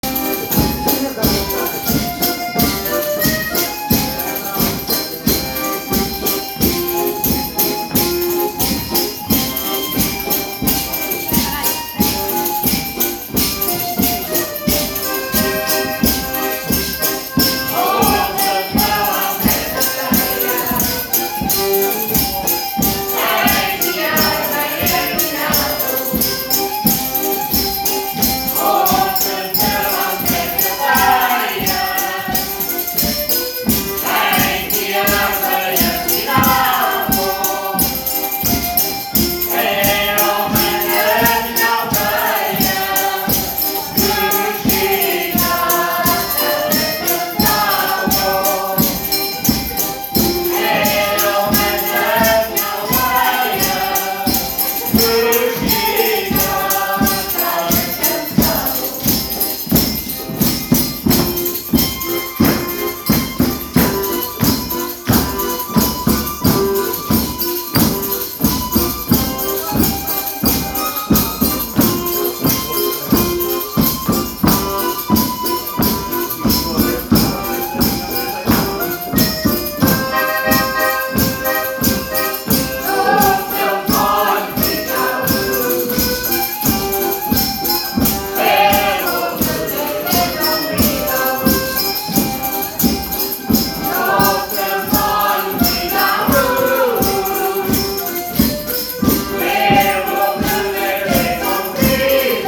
Os 16 dançarinos alinham-se nos pares habituais, enquanto se começa a afinar e a ouvir os sons familiares do acordeão, das pandeiretas, do adufe e do reco-reco.
Início do ensaio do Grupo de Grupo de Danças e Cantares da Vila do Carvalho no dia 2 de Maio